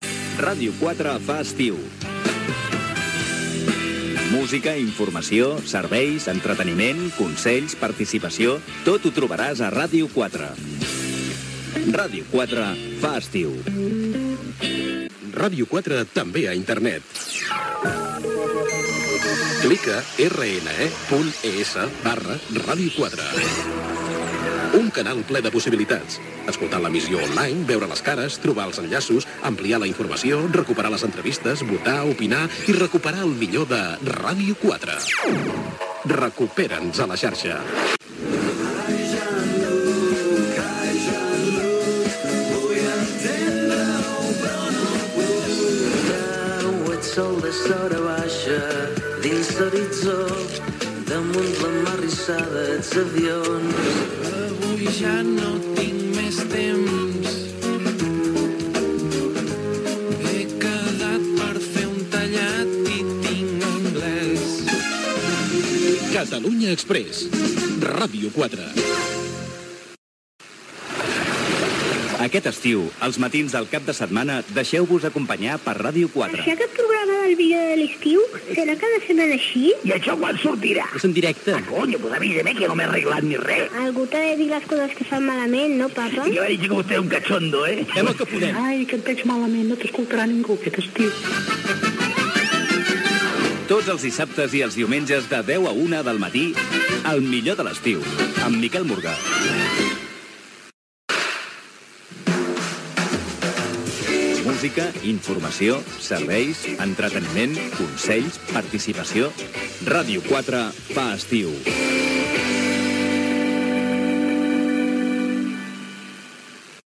Indicatiu d'estiu, Ràdio 4 a Internet, promocions "Catalunya exprés" i "El millor de l'estiu", indicatiu de la ràdio